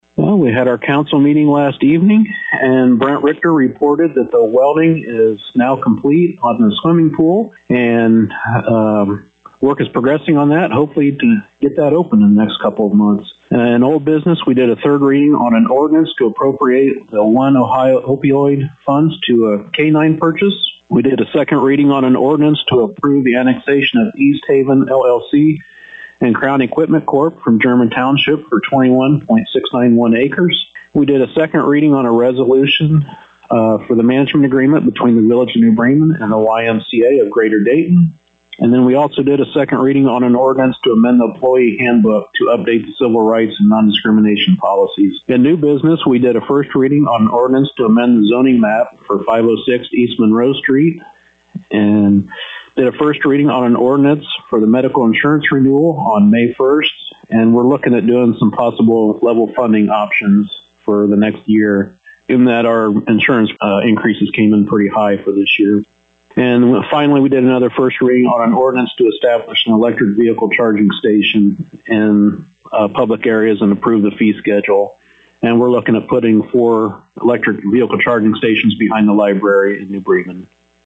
To hear the summary of the 3-24 meeting with New Bremen Mayor Bob Parker: